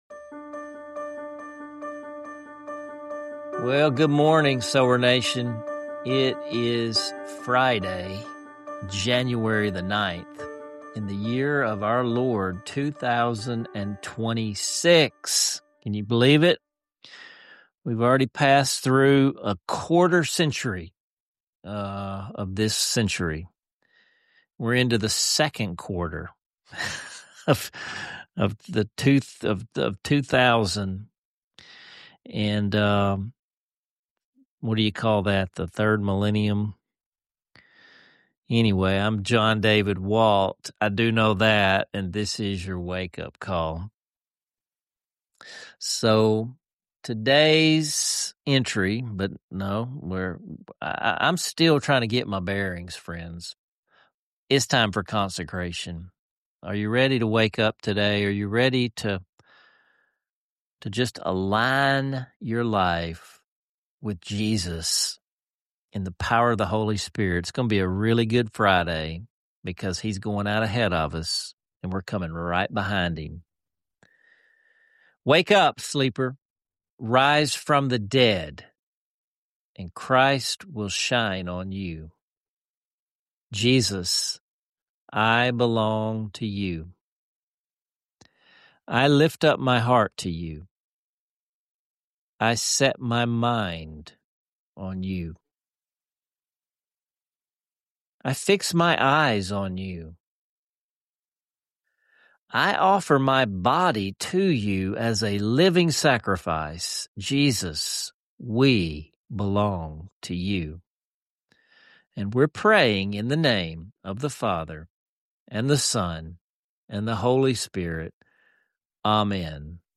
The practical, soulful exercise of “digging a footing” for God’s Word each morning, illustrated with a riveting father-son dialogue. A moving hymn sing-along that not only stirs nostalgia but also demonstrates how music becomes a spiritual anchor in the chaos of modern life.